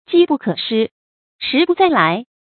jī bù kě shī，shí bù zài lái
机不可失，时不再来发音